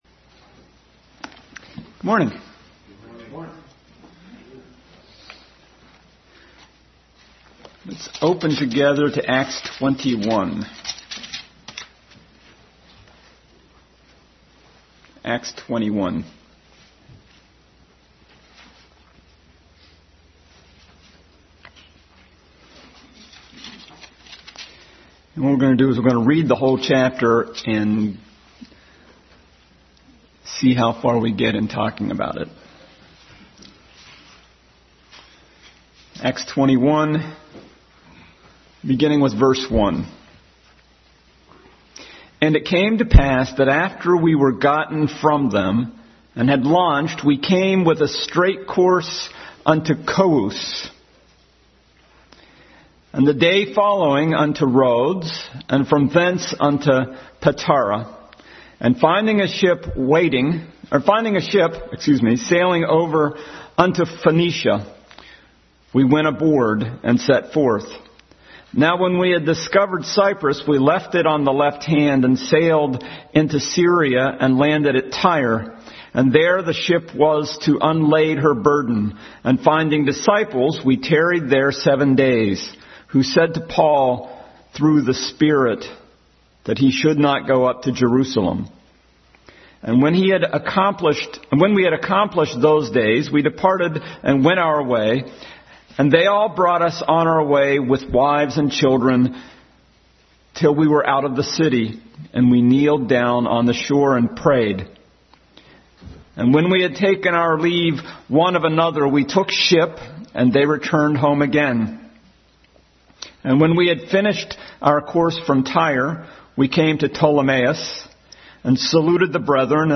Bible Text: Acts 21 | Regular Sunday School study in the book of Acts.
Acts 21 Service Type: Sunday School Bible Text